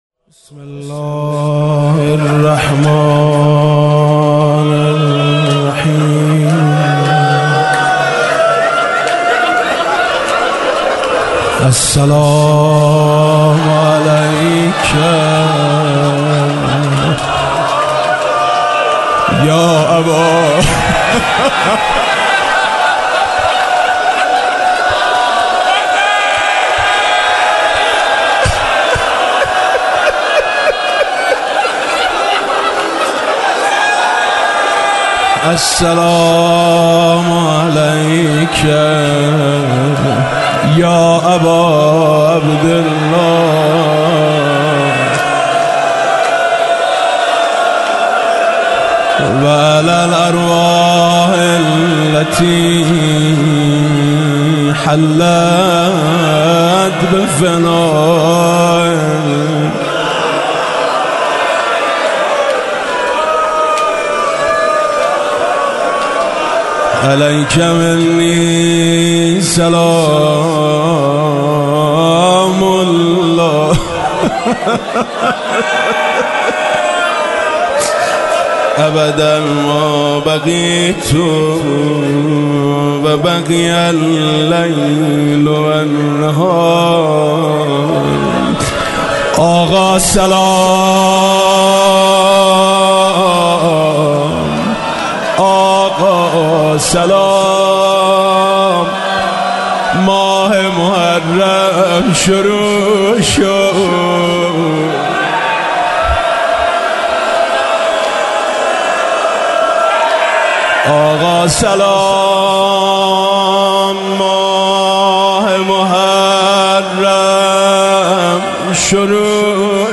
روضه شب اول.mp3
روضه-شب-اول.mp3